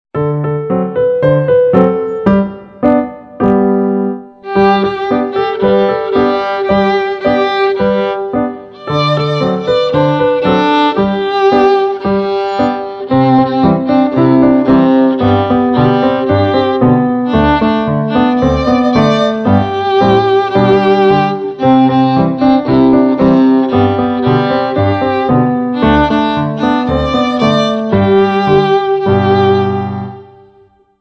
Besetzung: Viola